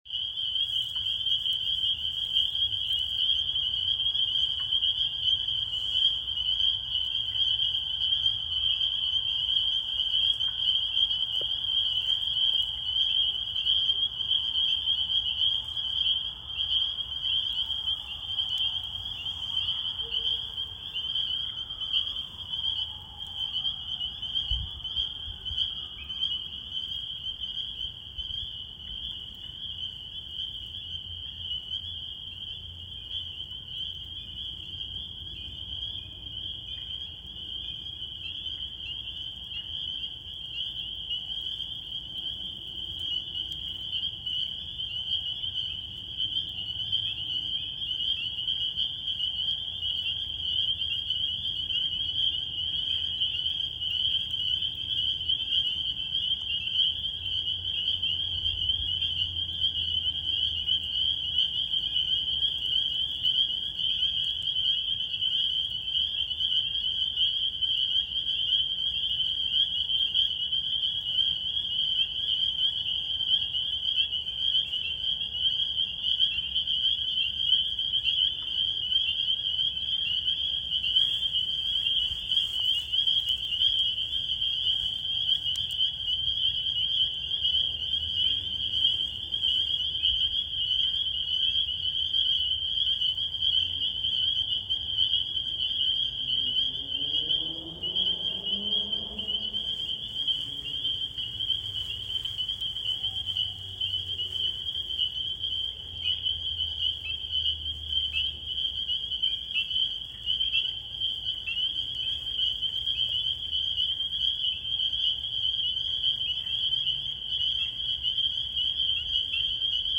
Typically, the second species to vocalize is the Spring Peeper (Pseudacris crucifer), which can also start calling in March. Peeper calls often sound like a short ascending whistle, or several clear peeps, and are difficult to misinterpret, especially when they are chorusing.
Spring_peeper.m4a